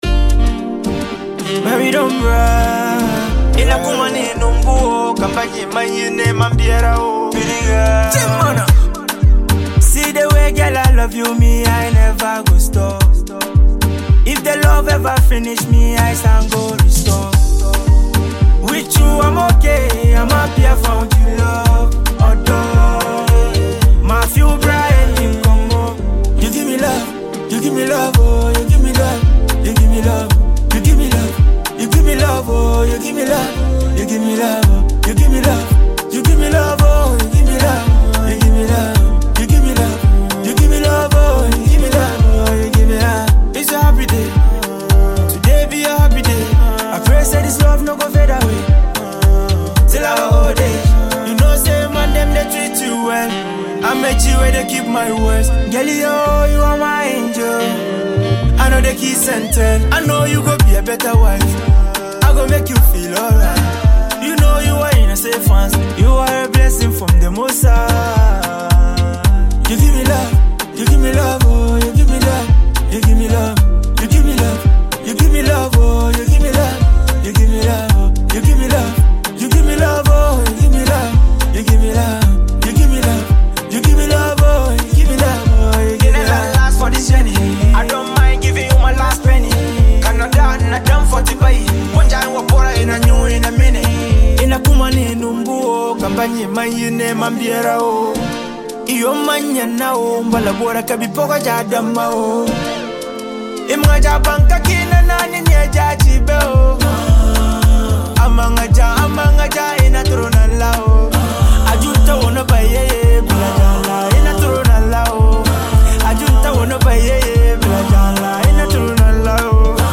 afrobeat track
love anthem
soulful vocals
an infectious beat that will get you moving